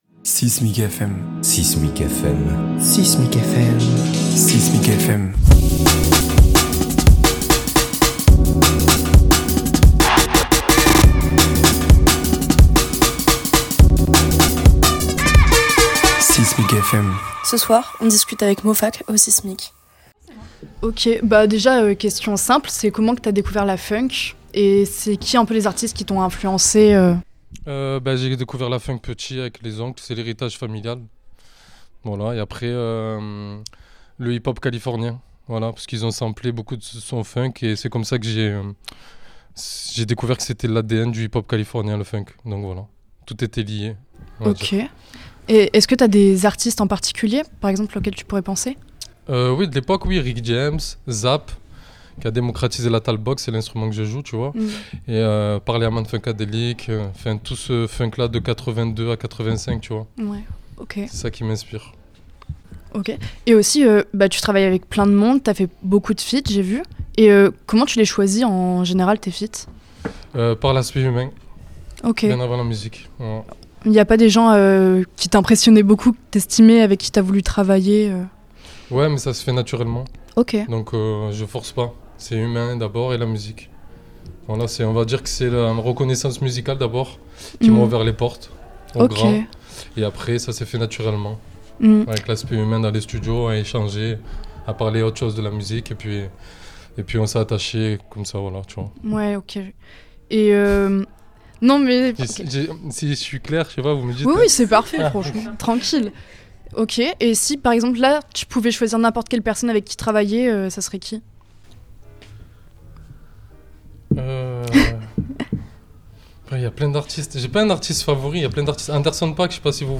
6mic FM est une nouvelle collaboration entre 6mic et RadioZai où les musiciens discutent avec nous dans ce lieu emblématique d'Aix-en-Provence.